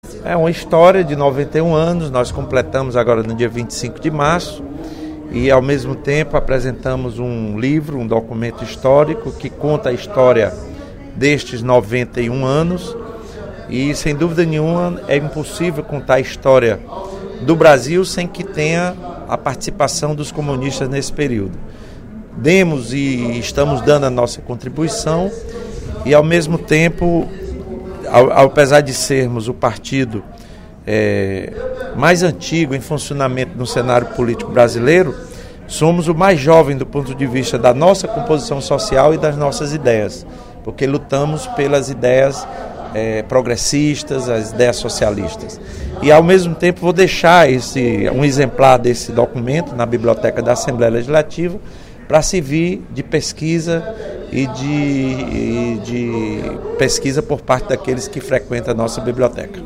O deputado Lula Morais (PCdoB) ocupou a tribuna do Plenário 13 de Maio, na sessão desta quarta-feira (27/03) para comemorar os 91 anos de criação do Partido Comunista do Brasil.